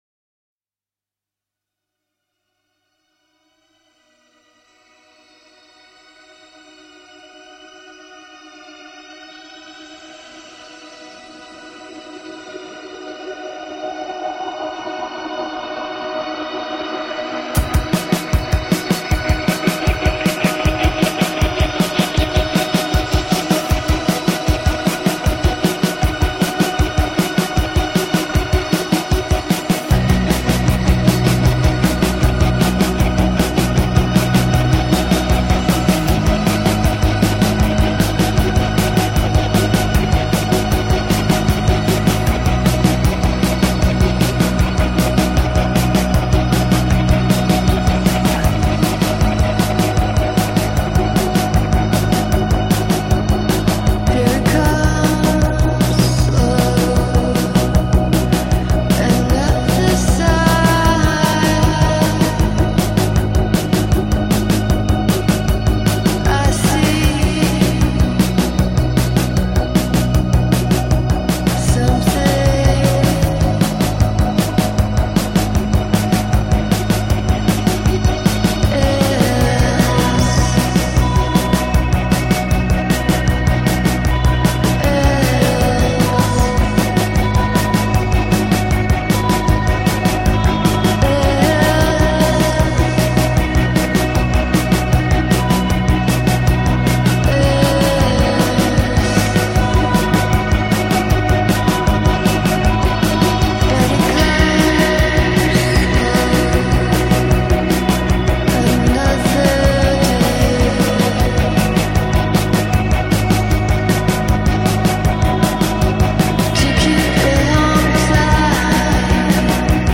noise pop duo
It’s got a beautiful steady build […]